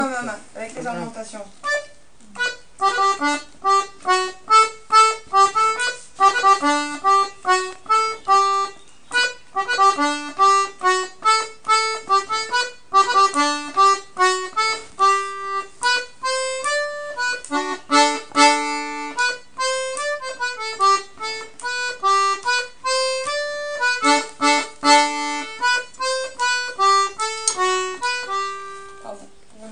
l'atelier d'accordéon diatonique
a mama ornementations
a mama ornementations.mp3